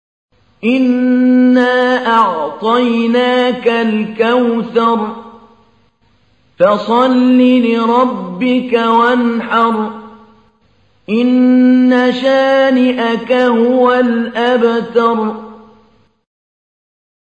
سورة الكوثر / القارئ محمود علي البنا / القرآن الكريم / موقع يا حسين